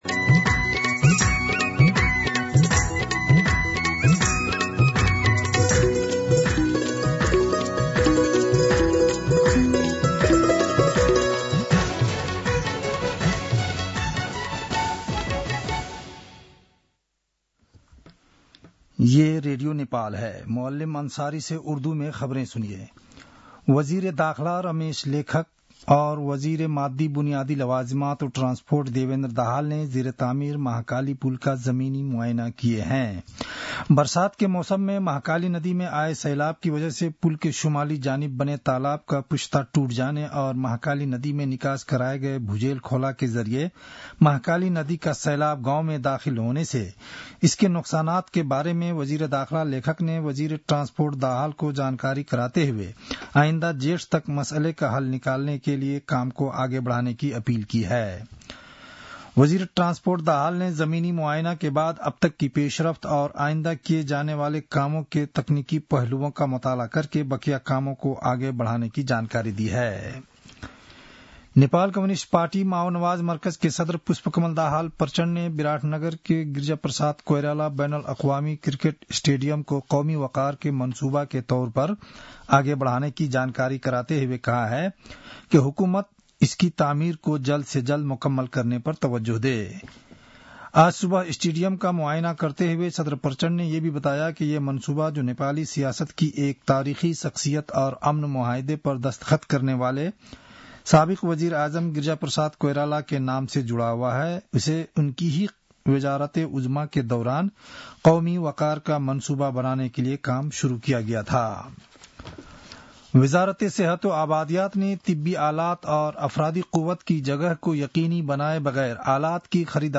उर्दु भाषामा समाचार : ६ माघ , २०८१
Urdu-News-10-5.mp3